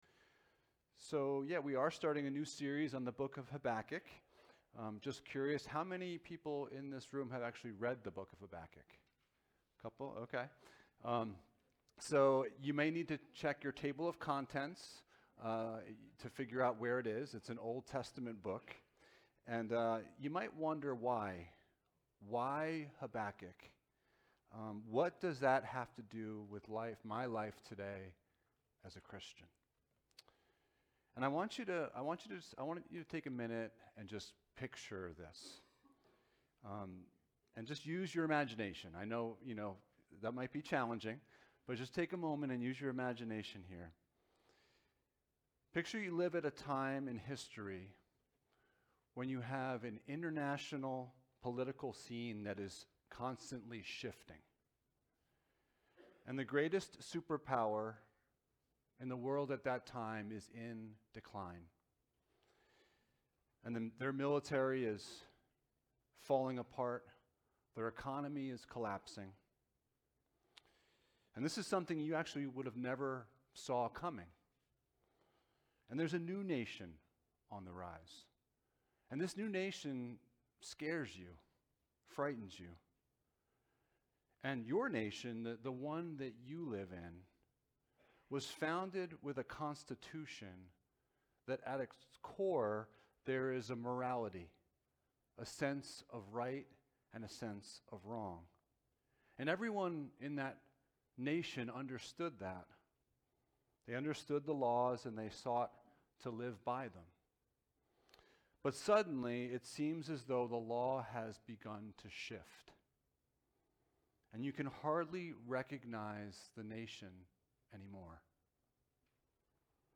Passage: Habakkuk 1:1-5 Service Type: Sunday Morning